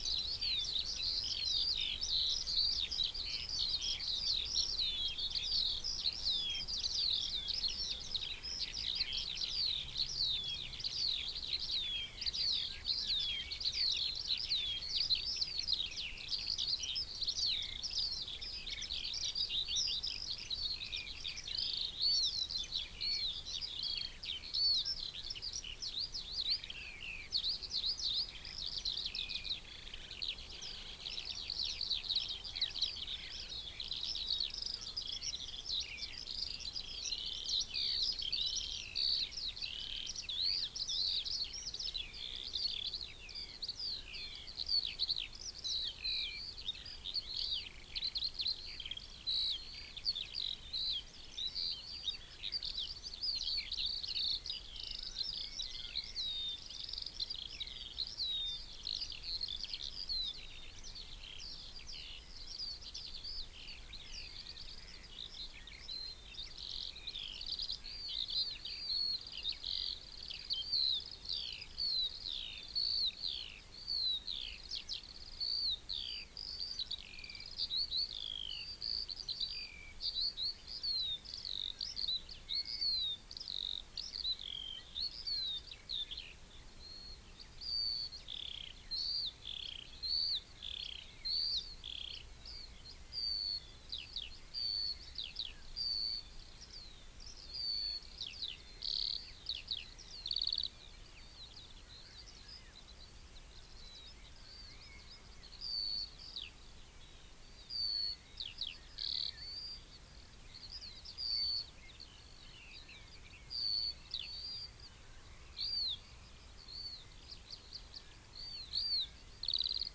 Columba palumbus
Corvus corone
Buteo buteo
Alauda arvensis
Sylvia communis